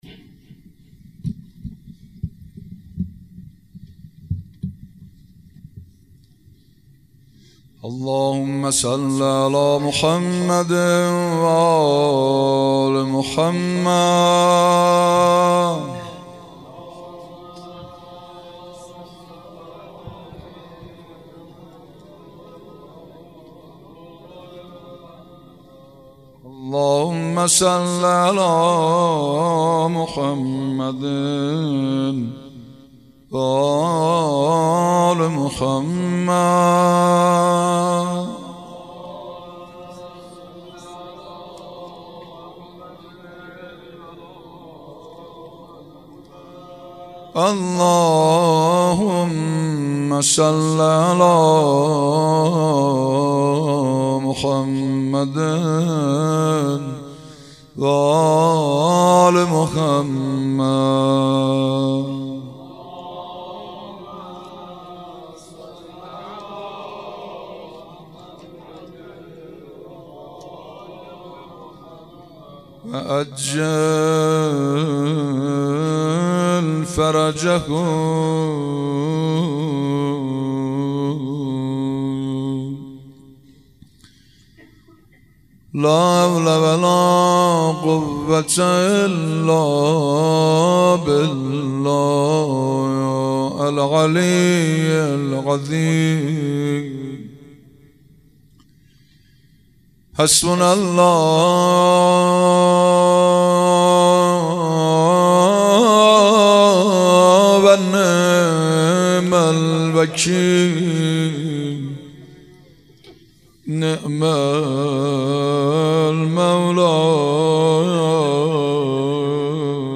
روضه و مناجات
روضه محمود کریمی